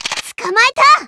Worms speechbanks
collect.wav